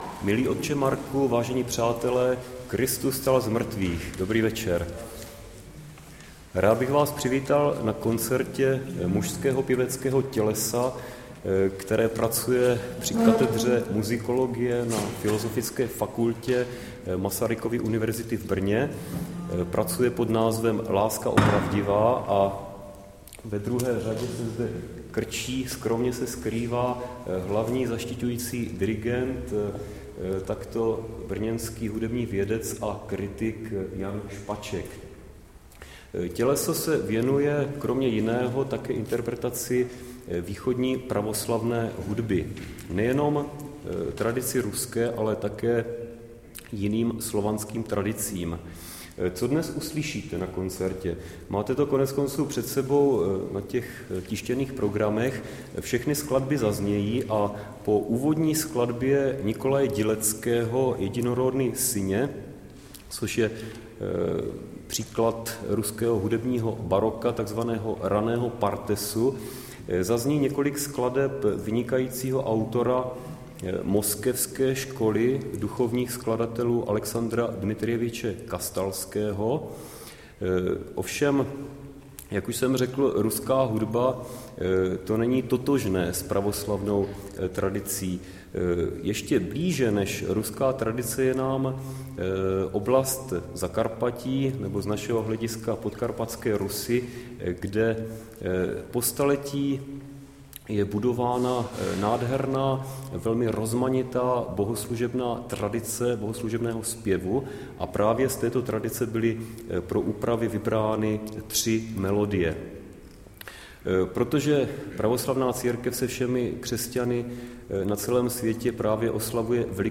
02-Proslov.mp3